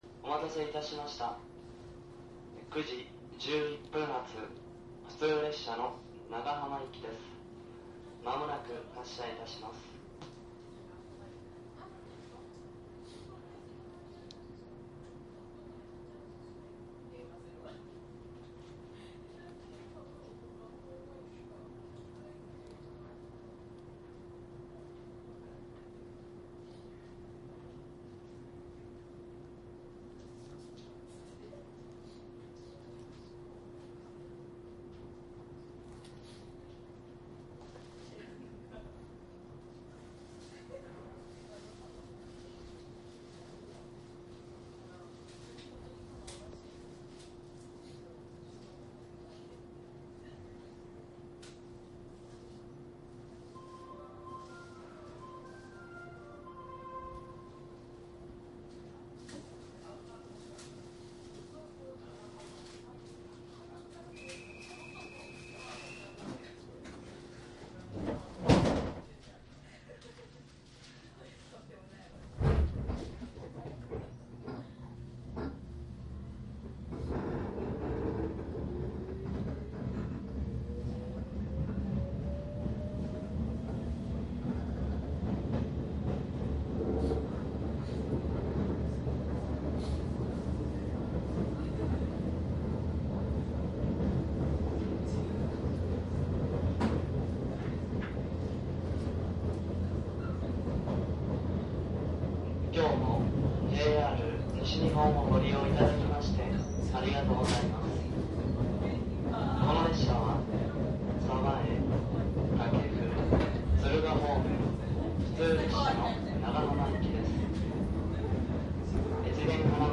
♪ＪＲ西日本４１９系 北陸本線 　鉄道走行音ＣＤ★
① ４１９系 （モハ418-6）
ＪＲ北陸本線 （普通）福井→敦賀
注意事項収録機材は、ソニーDATと収録マイクソニーECM959を使用